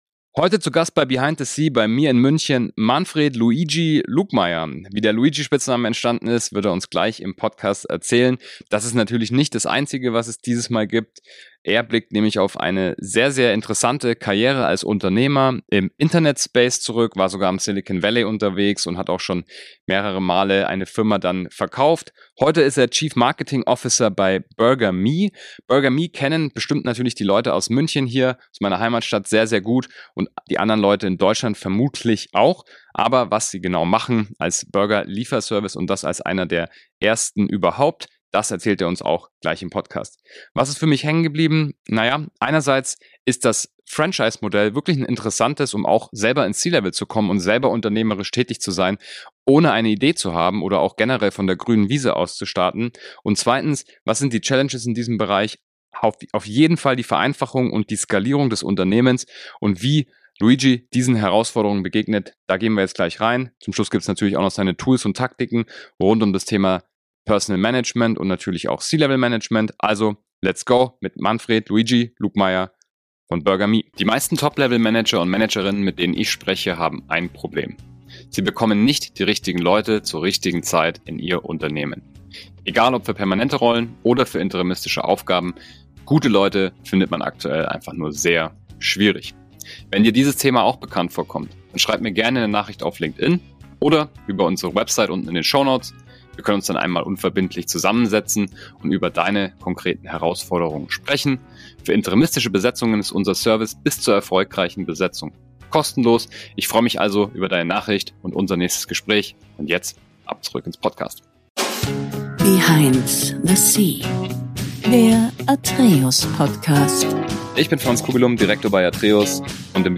im gemeinsamen Gespräch